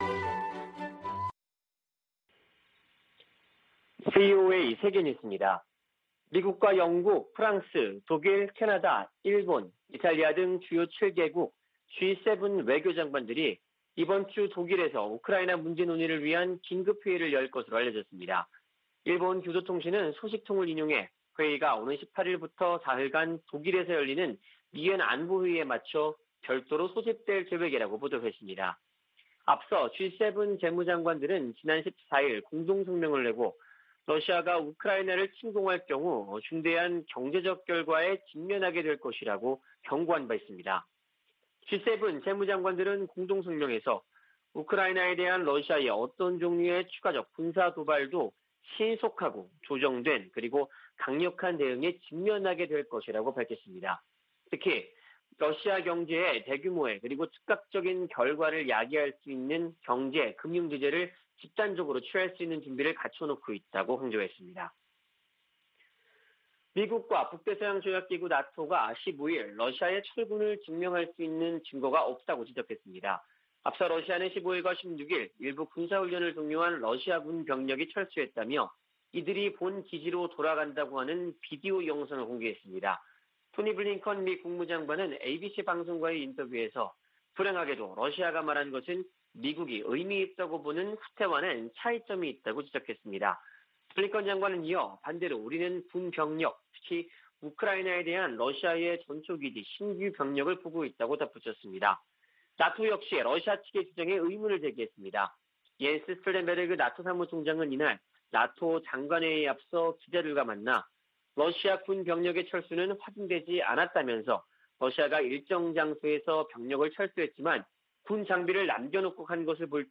VOA 한국어 아침 뉴스 프로그램 '워싱턴 뉴스 광장' 2021년 2월 17일 방송입니다. 웬디 셔먼 미 국무부 부장관이 일본 외무성 사무차관과 전화 협의를 갖고 북한에 진지한 외교 복귀를 촉구했습니다.